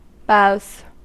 Ääntäminen
Ääntäminen US Tuntematon aksentti: IPA : /ˈbaʊz/ Haettu sana löytyi näillä lähdekielillä: englanti Käännöksiä ei löytynyt valitulle kohdekielelle. Boughs on sanan bough monikko.